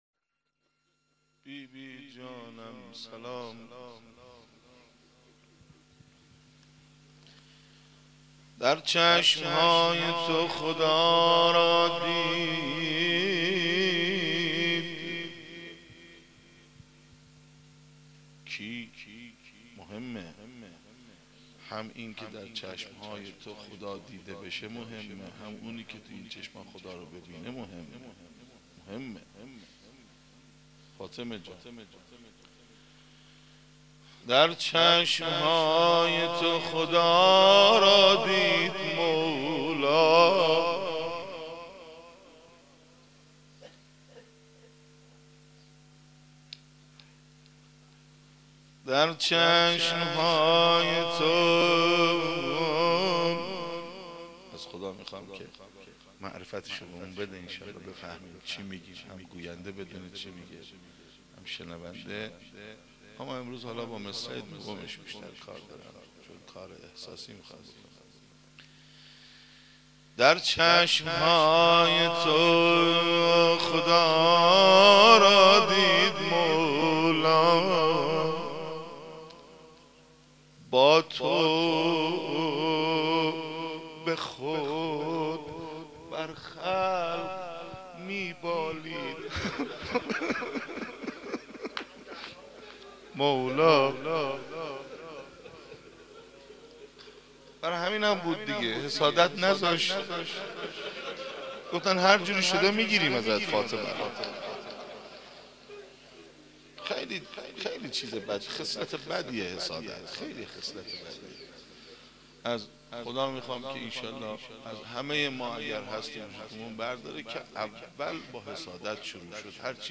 قالب : روضه